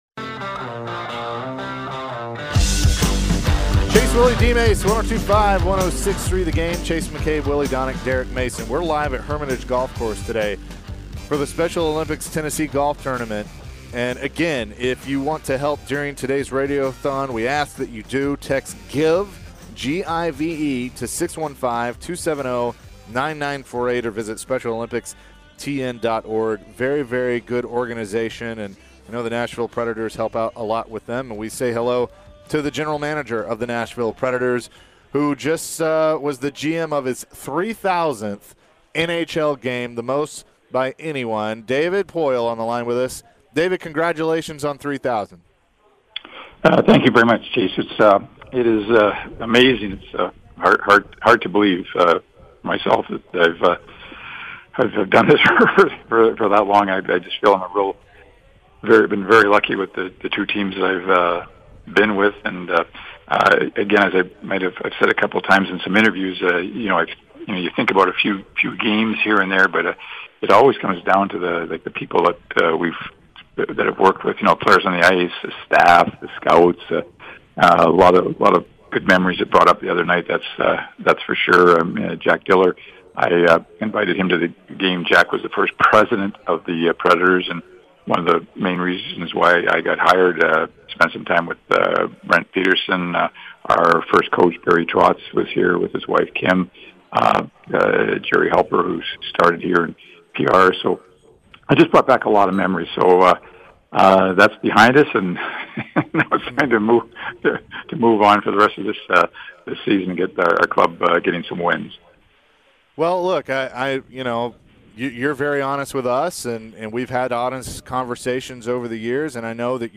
David Poile interview (10-25-22)